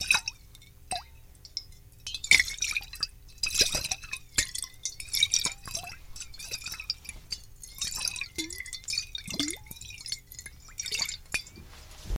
Bottle Sloshing Around, Half Full Perrier